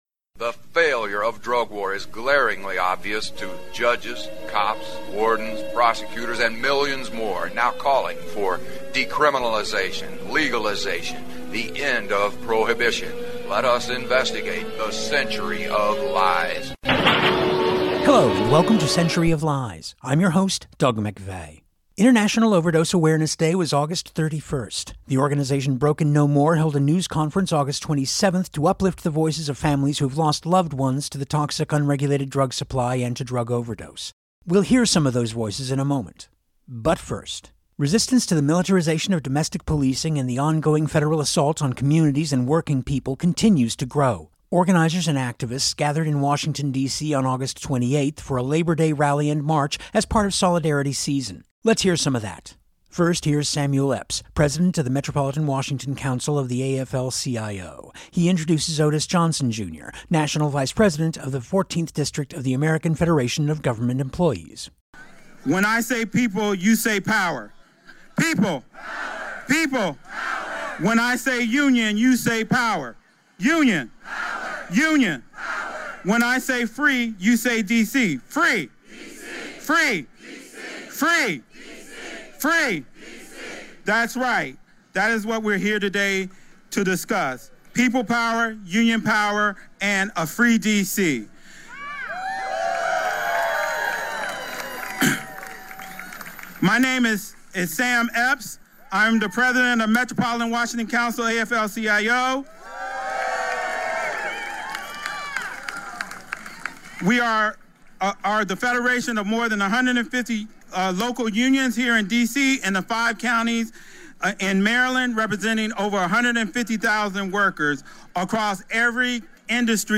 The organization Broken No More held a news conference August 27 to lift up the voices of families who’ve lost loved ones to the toxic unregulated drug supply and to drug overdose.
Plus, resistance to the militarization of domestic policing and the ongoing federal assault on communities and working people continues to grow. Organizers and activists gathered in Washington DC on August 28 for a Labor Day Rally and March as part of Solidarity Season.